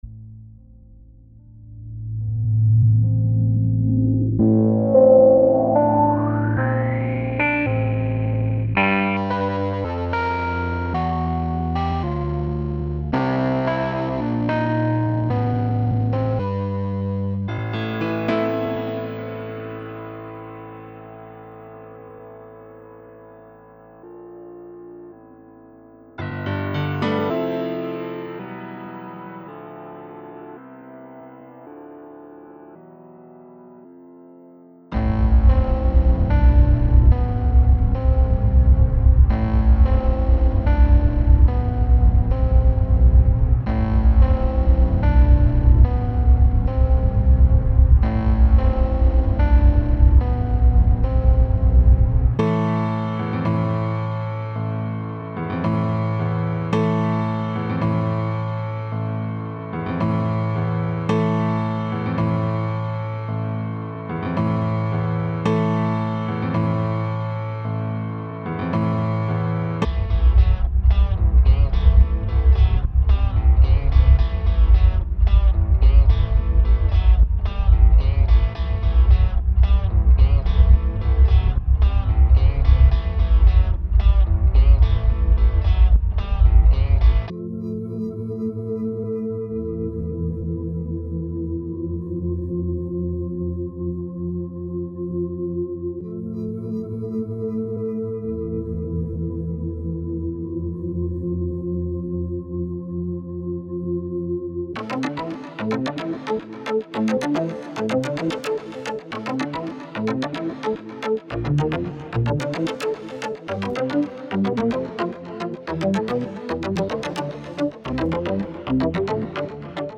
• Жанр: Электронная